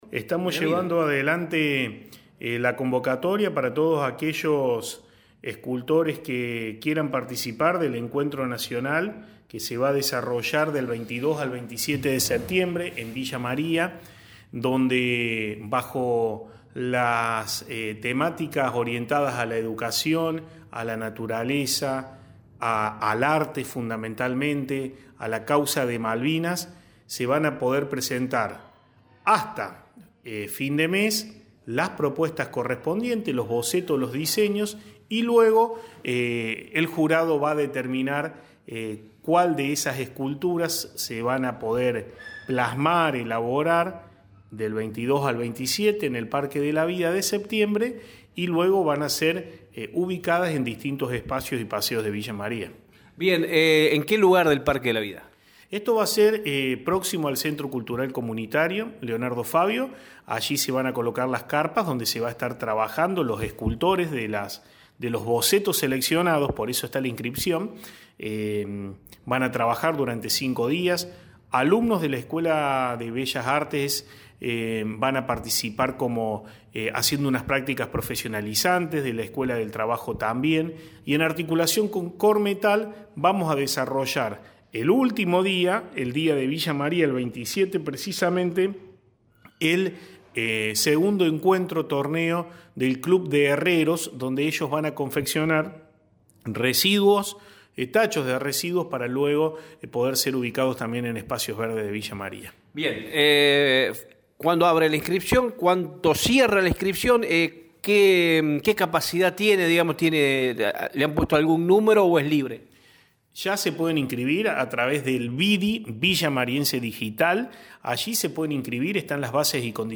El secretario de Gobierno, Cultura y Relaciones Institucionales, Marcos Bovo, manifestó que la convocatoria para participar del miesmo estará abierta hasta el domingo 31 de agosto.